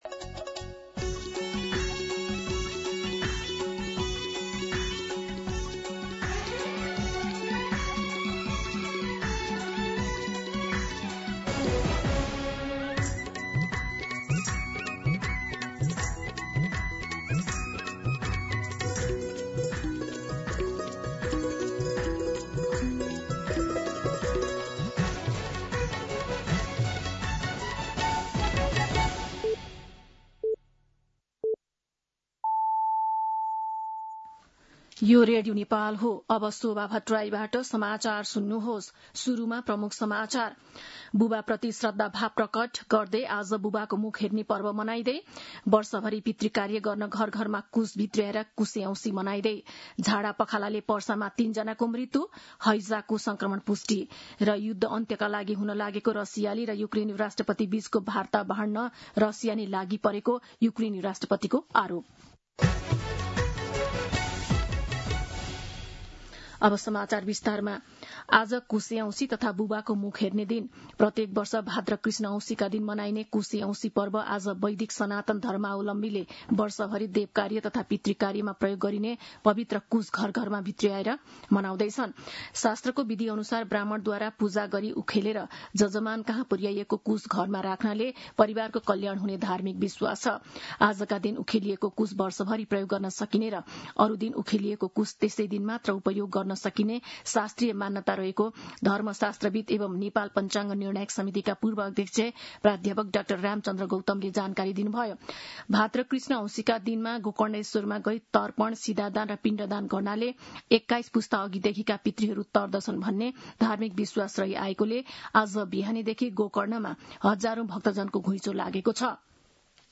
दिउँसो ३ बजेको नेपाली समाचार : ७ भदौ , २०८२
3pm-Nepali-News.mp3